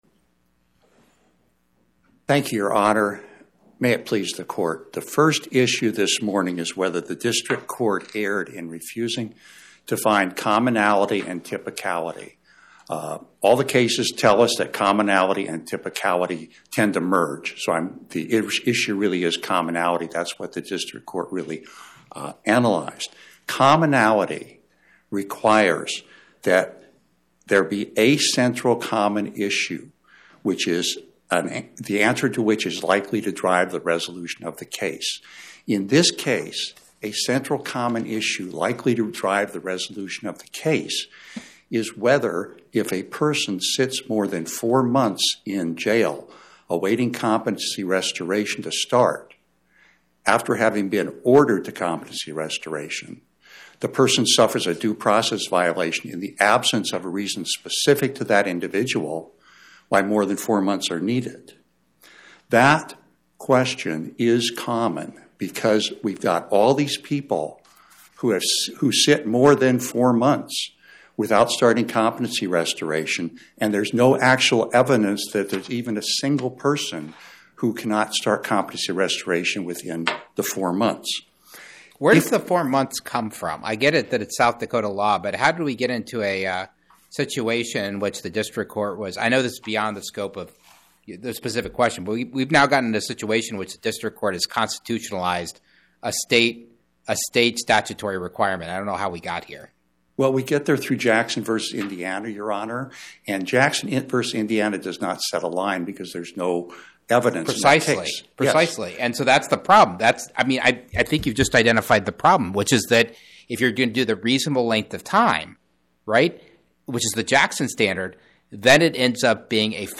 Oral argument argued before the Eighth Circuit U.S. Court of Appeals on or about 02/13/2026